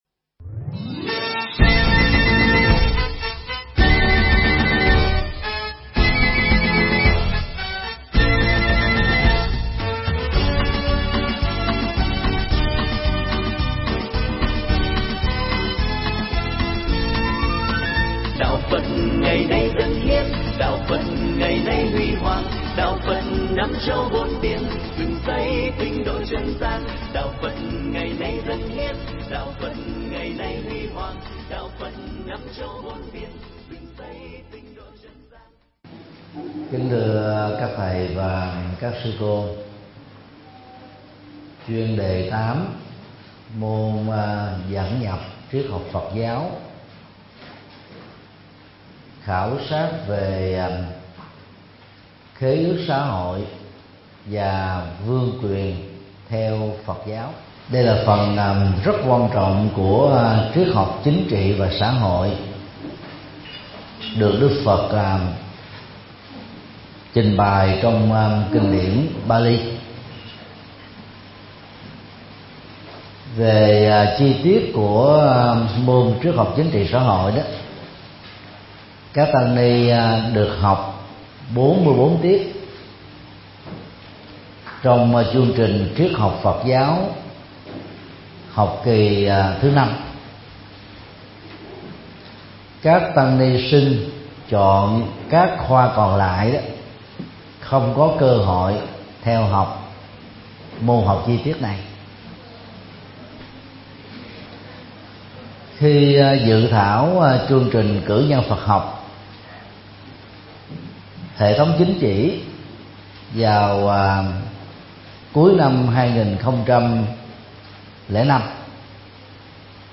Mp3 Thuyết Pháp Triết Học PG Bài 7: Khảo Sát Về Khế Ước Xã Hội Và Vương Quyền Theo PG
giảng tại Học Viện Phật Giáo Việt Nam